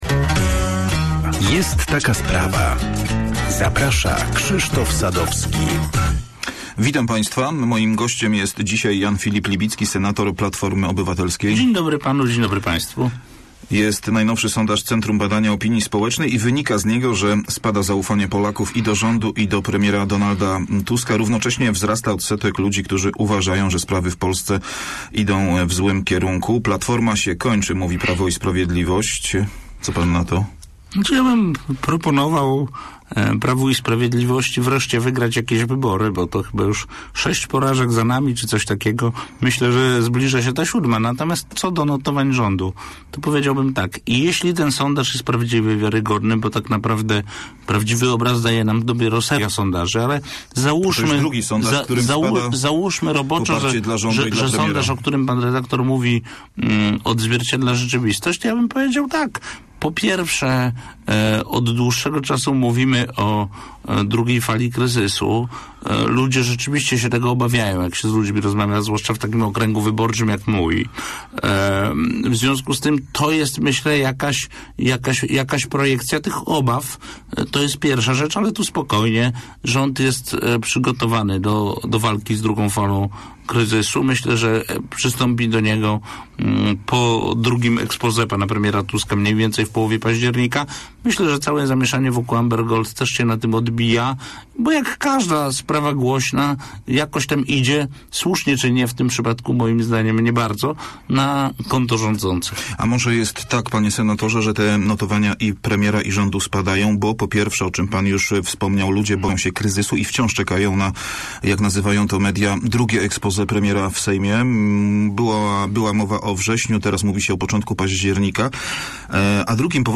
- Minister sprawiedliwości Jarosław Gowin ma rację. To rząd się myli - mówił w porannej rozmowie Radia Merkury senator Platformy Obywatelskiej, Jan Filip Libicki. To komentarz wielkopolskiego parlamentarzysty do ostatniej decyzji rządu, który zapowiedział, że Polska podpisze Konwencję Rady Europy o przeciwdziałaniu i zwalczaniu przemocy wobec kobiet oraz przemocy domowej.